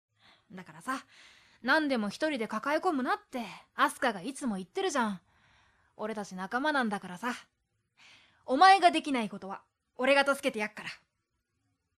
【サンプルセリフ】
（弱音を吐く柾宮に、勇気付けようと）